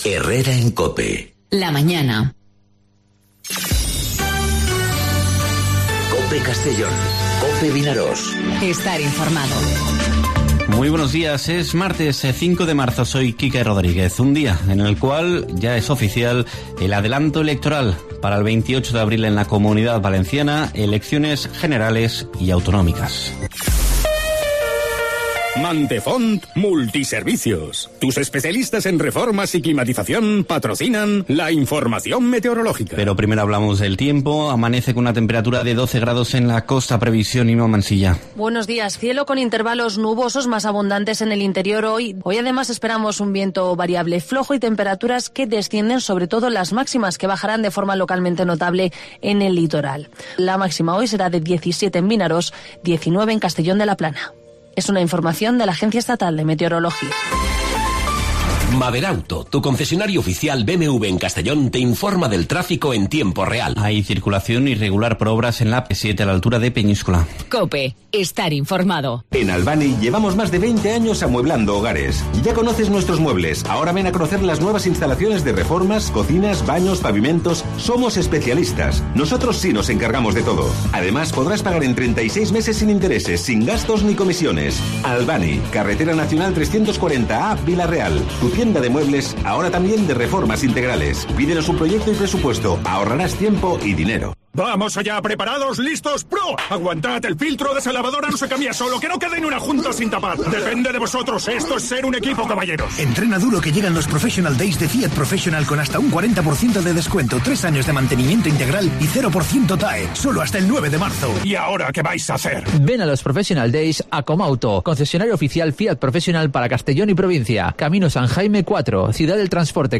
Informativo Herrera en COPE Castellón (05/03/2019)